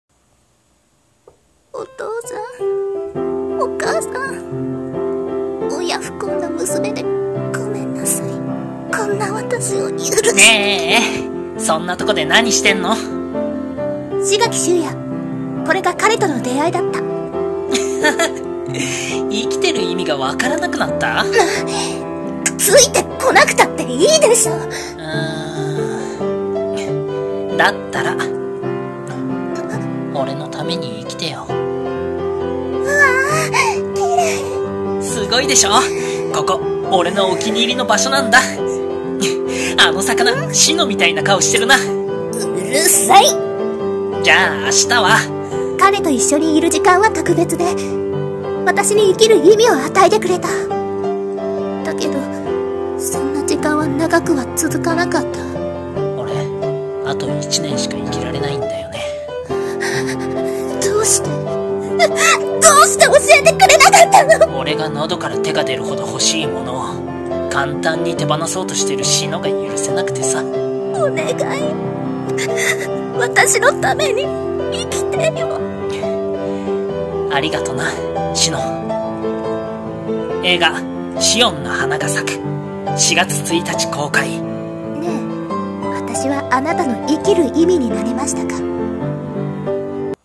声劇台本「シオンの花が咲く」映画予告風